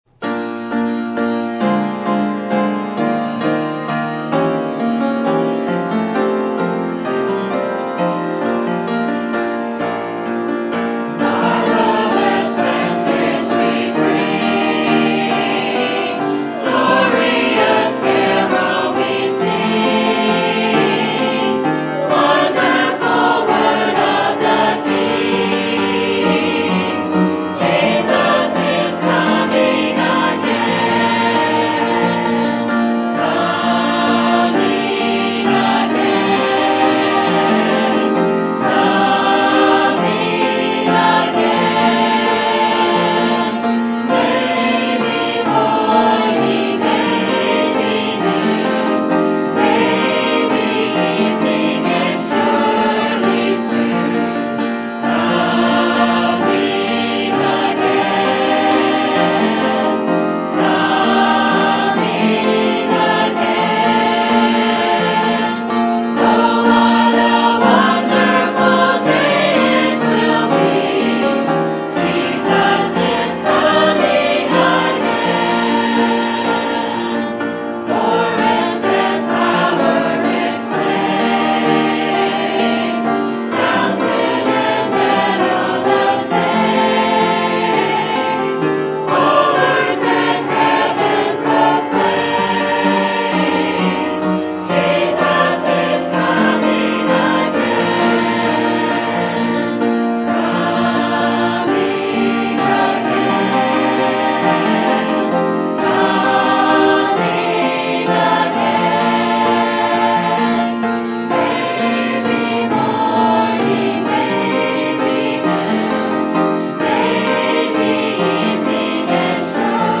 Sermon Topic: General Sermon Type: Service Sermon Audio: Sermon download: Download (23.89 MB) Sermon Tags: 1 John Assurance Salvation Tests